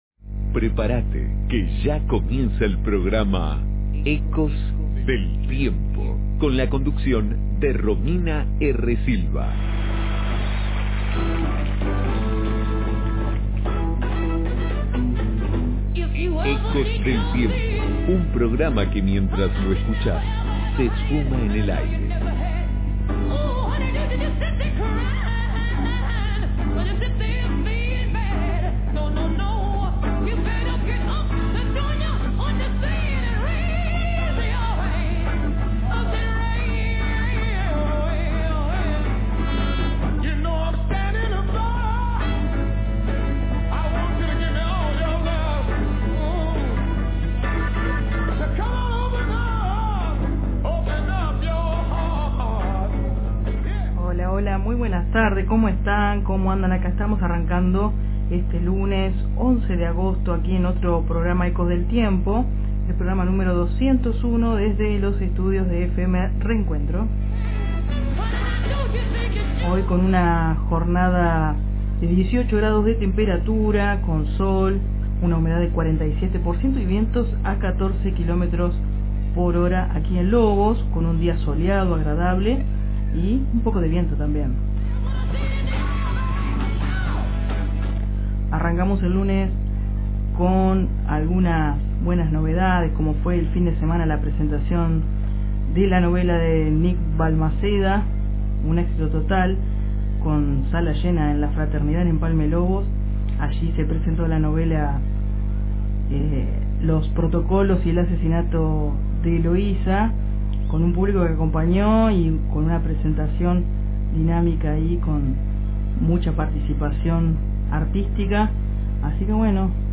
✨ Entrevistas